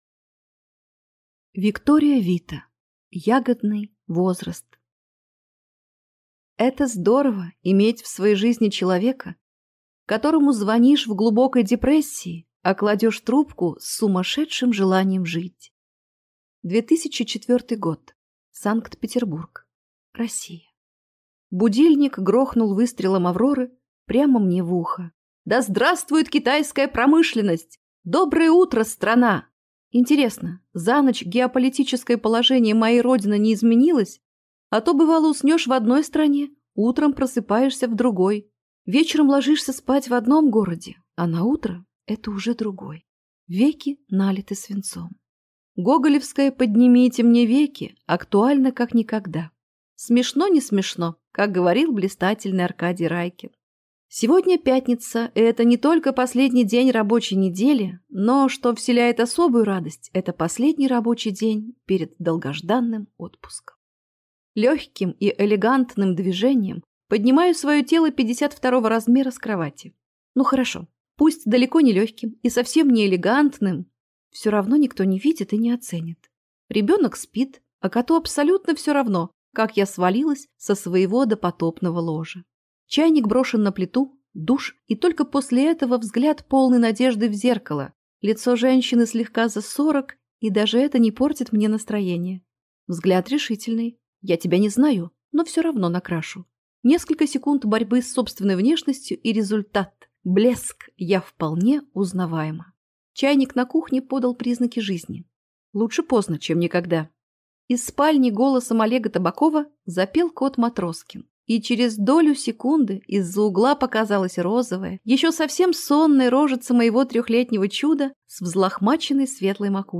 Аудиокнига Ягодный возраст | Библиотека аудиокниг